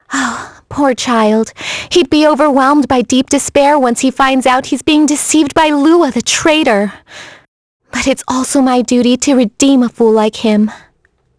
Isaiah-vox-dia_01.wav